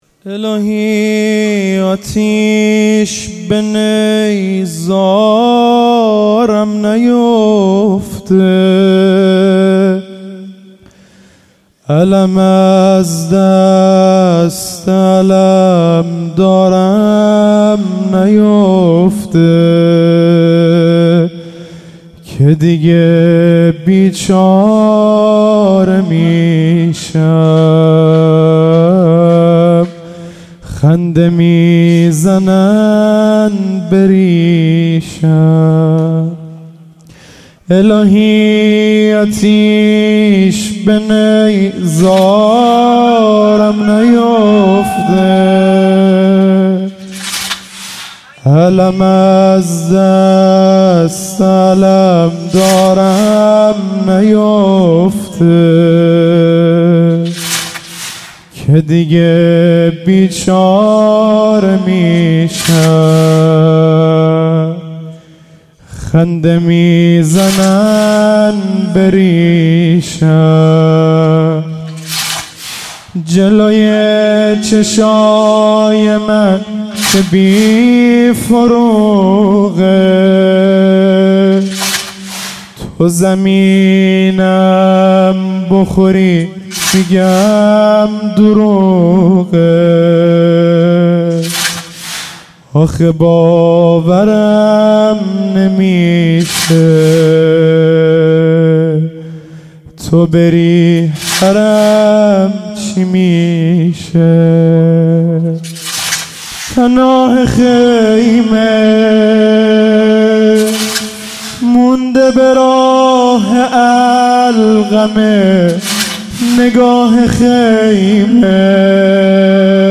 شب تاسوعا محرم95/هیئت انصارالحجه عج الله (مشهد)